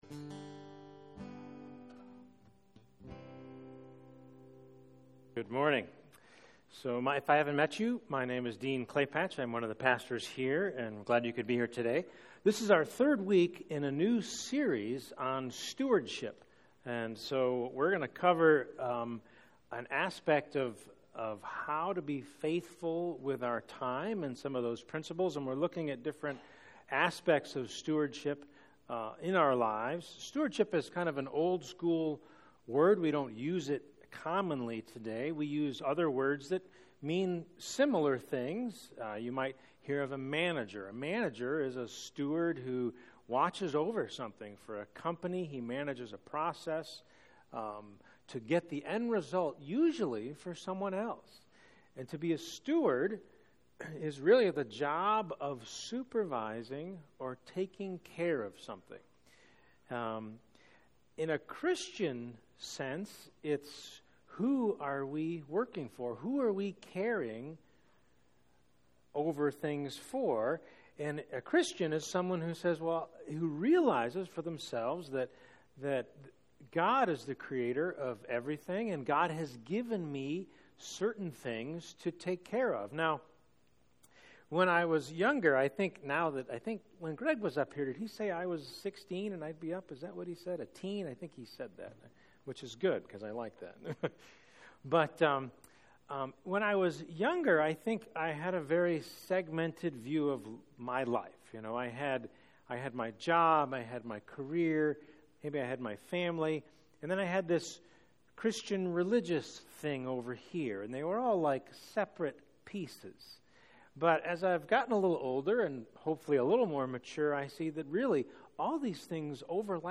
Faithfulness & Stewardship Service Type: Sunday Morning %todo_render% « Giving